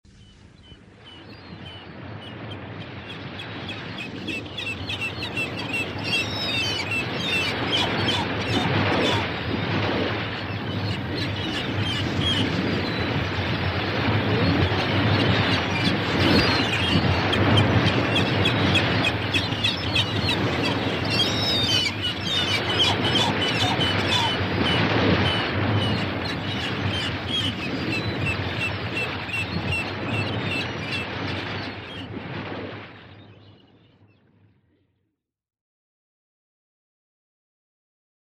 Catégorie Animaux